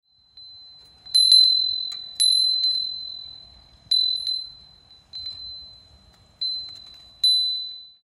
美しい竹と南部鉄風鈴の音で清涼を感じています…
鉄製の風鈴を選んだ理由は、ガラス製だと壊れたときに危ないことと、鉄製の風鈴のほうが音色が低めでやわらかいので耳障りでないからです。
とても美しい風鈴の音、涼やかで癒されます。
後半は、ちょっと指で揺らしました（汗）
そして音色は、とワクワクして聞いてみると…なんて優しい音！想像とは違って可愛らしい音色で(ｽﾐﾏｾﾝ)。
マイクを設置して録音した甲斐があります。
ちょっと低めのやわらかな音が魅力なんですね。